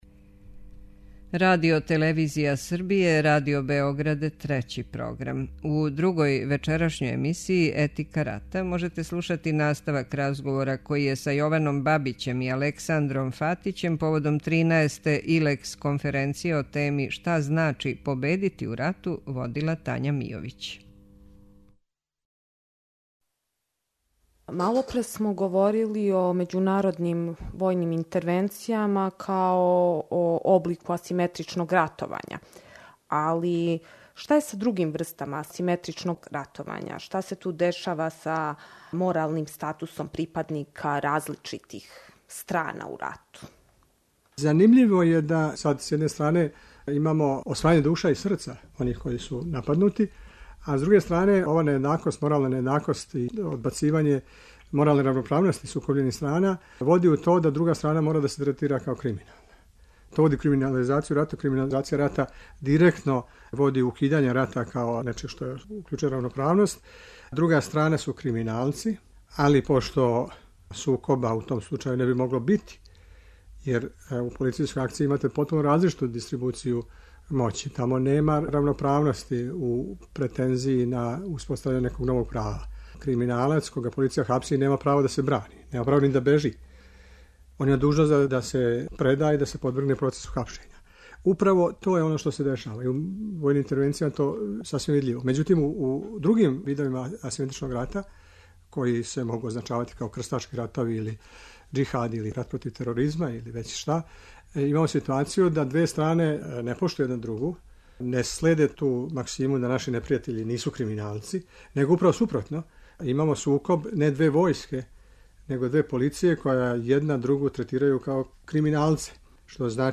Трибине – Шта значи победити у рату?